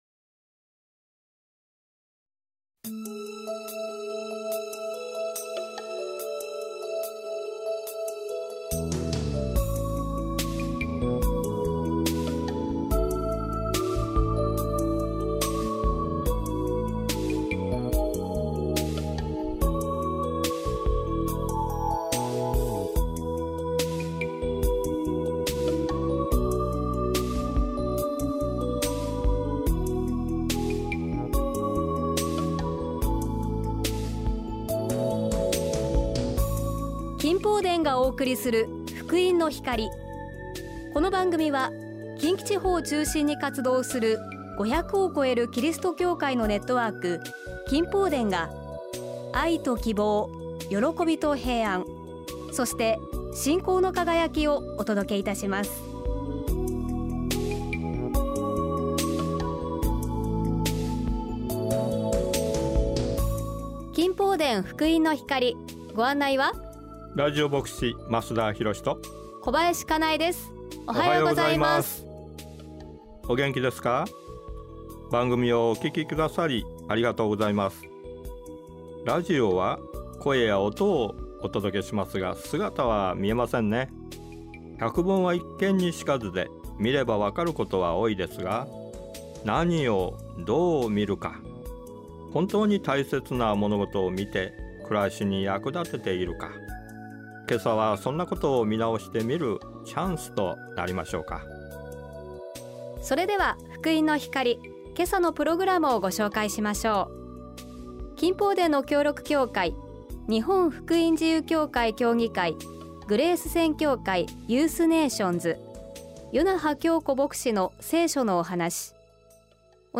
御言葉とお話
信仰体験談